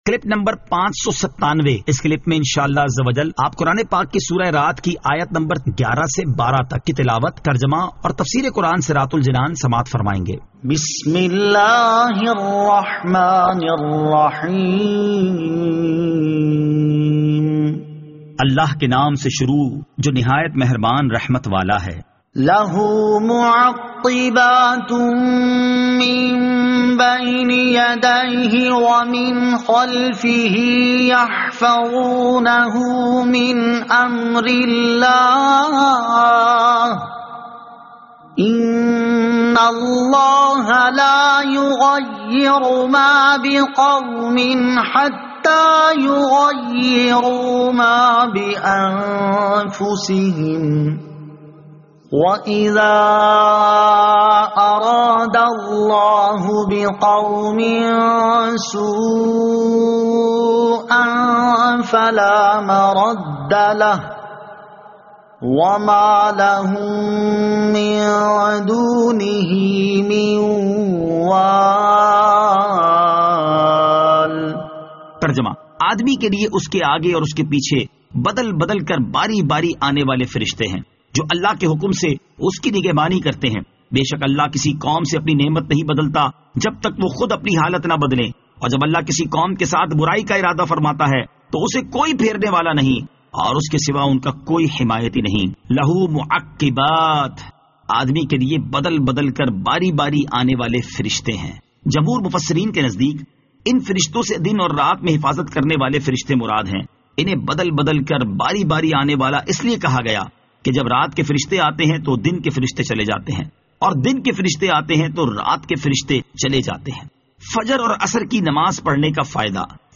Surah Ar-Rad Ayat 11 To 12 Tilawat , Tarjama , Tafseer